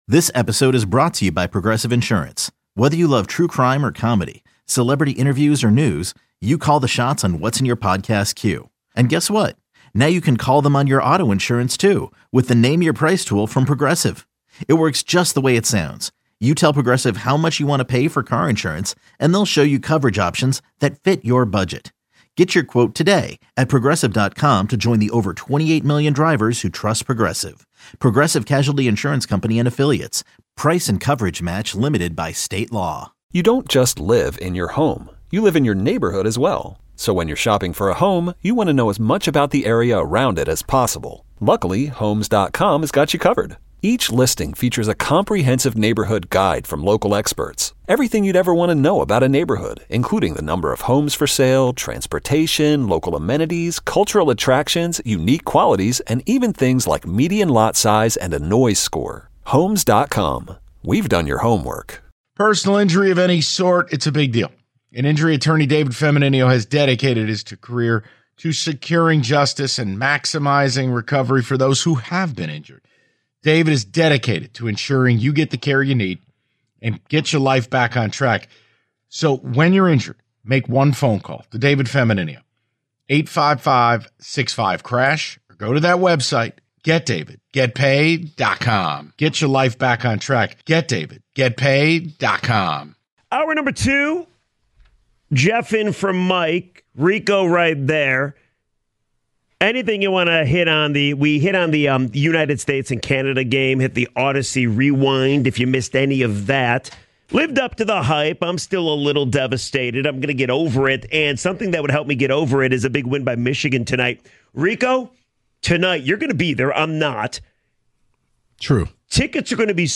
They take plenty of your calls and read your ticket texts throughout the hour.